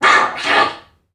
NPC_Creatures_Vocalisations_Robothead [14].wav